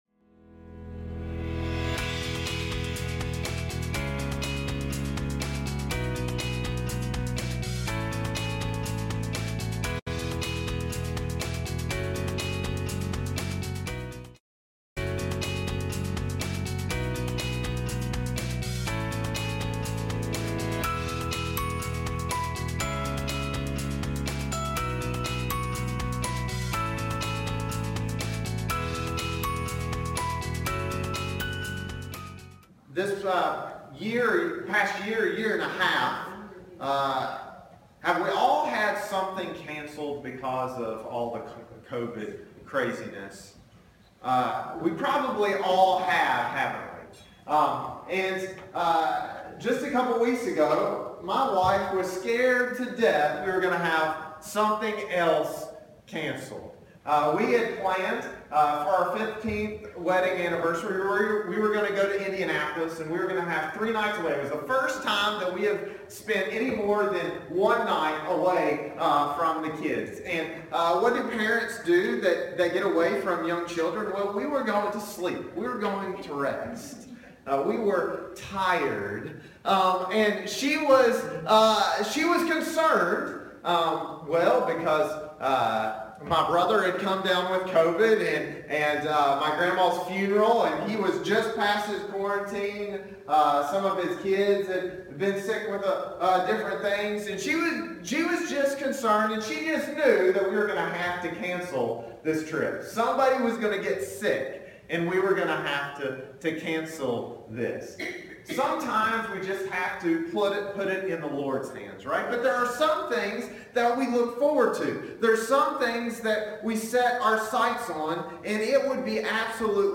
Passage: Philippians 3:1-11 Service Type: Sunday Morning Service